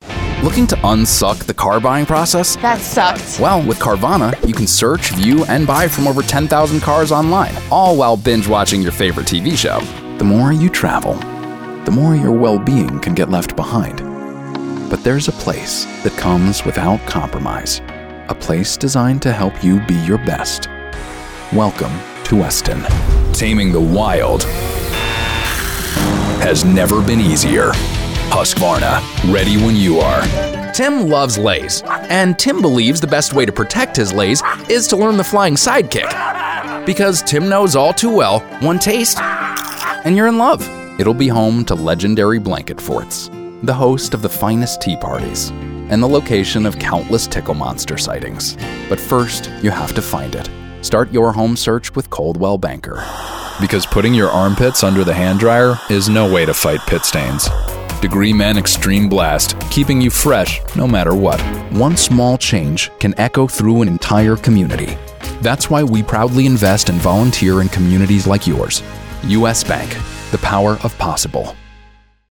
new york : voiceover : commercial : men